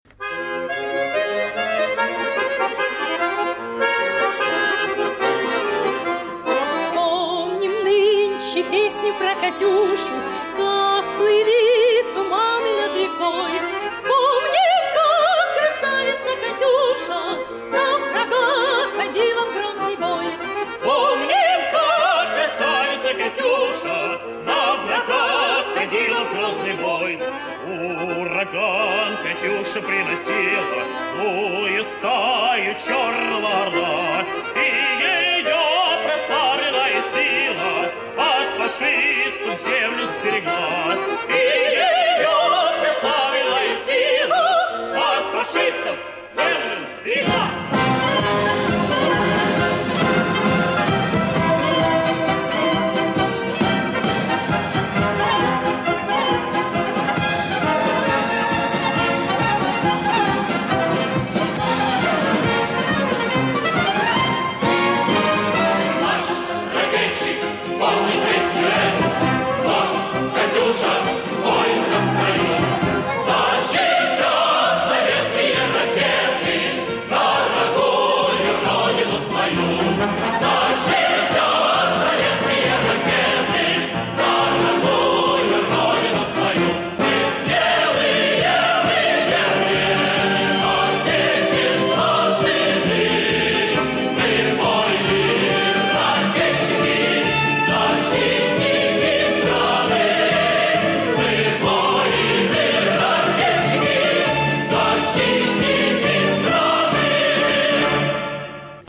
Маленький попурри